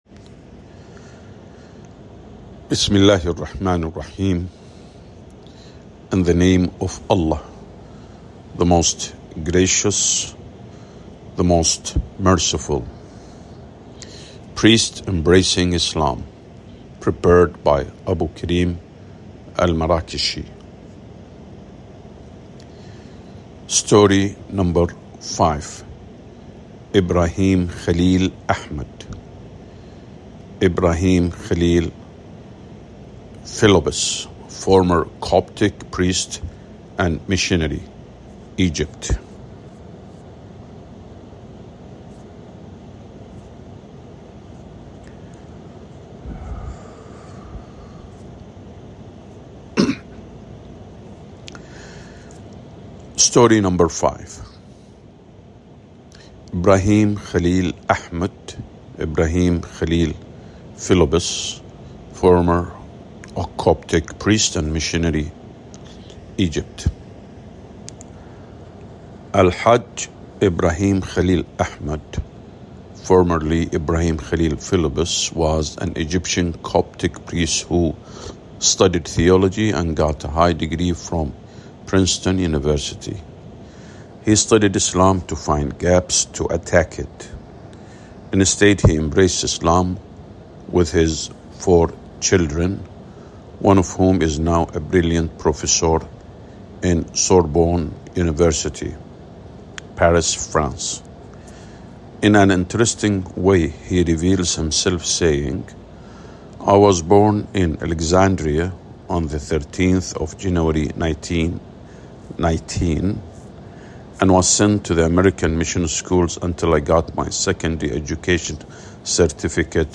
priests-embracing-islam_audiobook_english_5.mp3